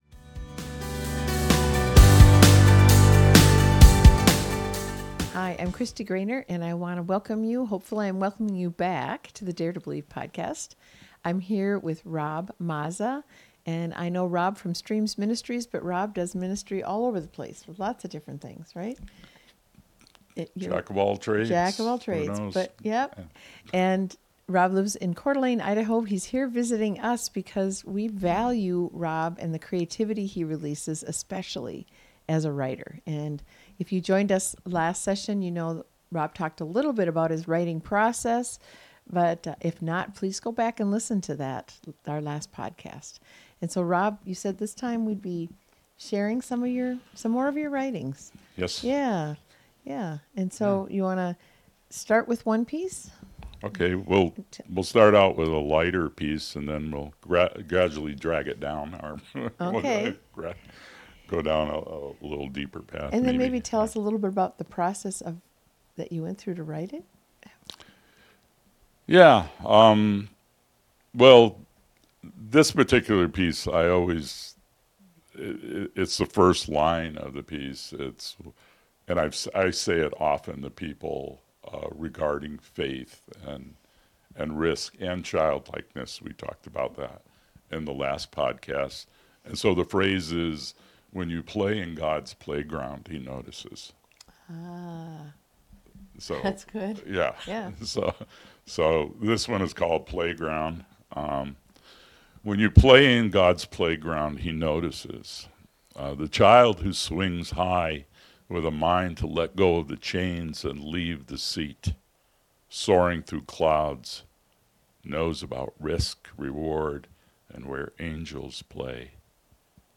This is a great conversation about what it looks like to create and write with God, and how writing can often become prophetic and minister powerfully to others when partnered with the Holy Spirit.